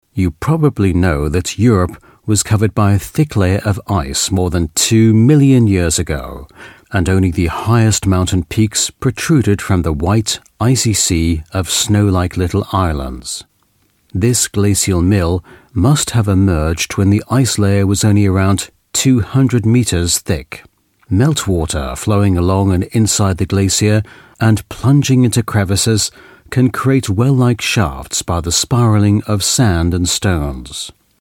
Native Speaker
Audioguides